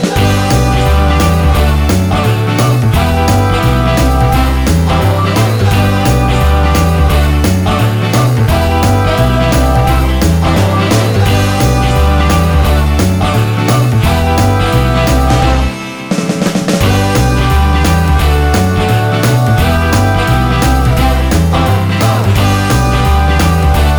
No Lead Saxophone Rock 'n' Roll 3:21 Buy £1.50